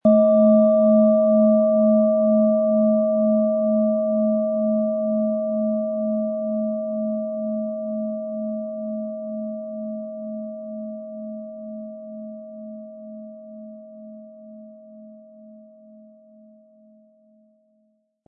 Von Hand getriebene Schale mit dem Planetenton Wasserstoffgamma.Die Klangschalen lassen wir von kleinen Manufakturen anfertigen.
PlanetentonWasserstoffgamma
MaterialBronze